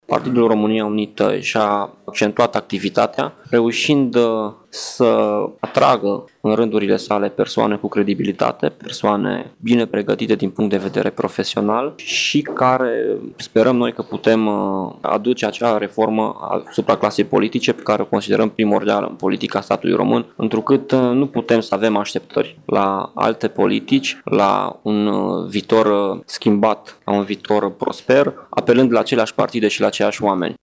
într-o conferință de presă, că formațiunea mizează pe candidați tineri, bine pregătiți din punct de vedere profesional și fără probleme de ordin penal: